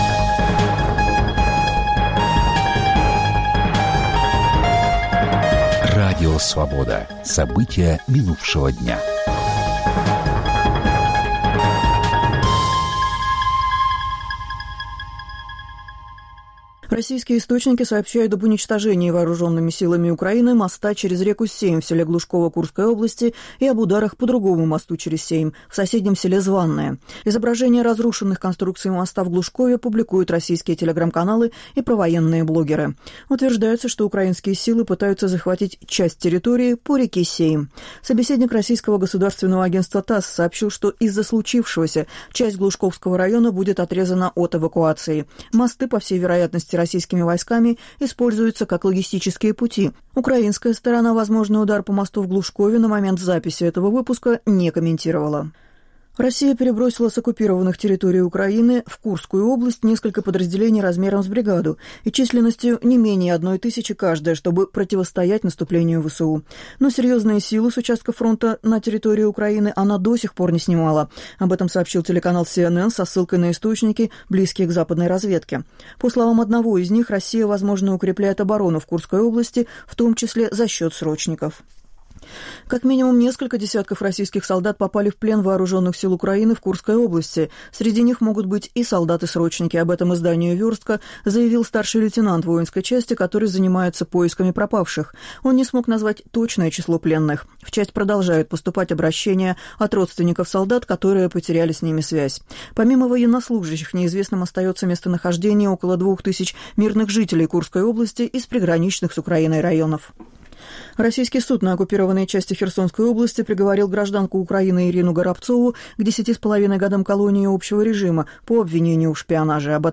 Новости Радио Свобода: итоговый выпуск